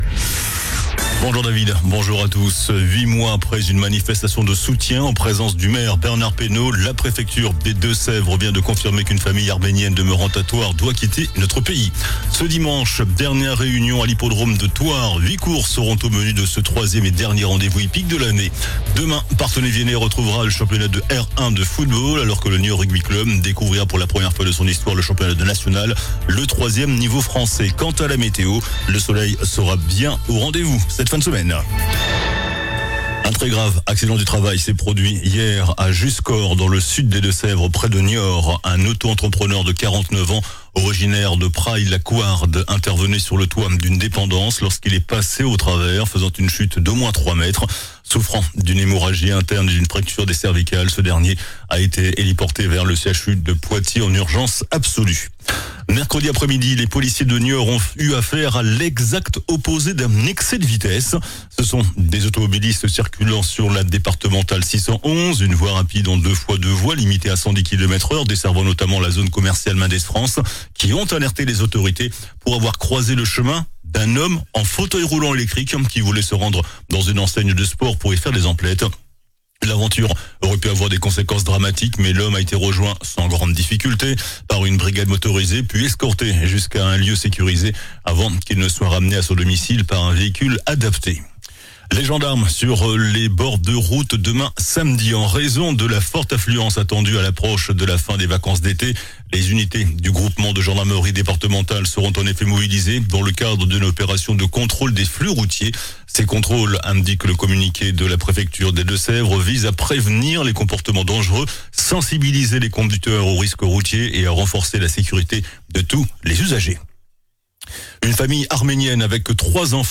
JOURNAL DU VENDREDI 22 AOÛT ( MIDI )